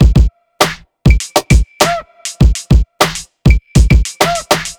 TI100BEAT1-R.wav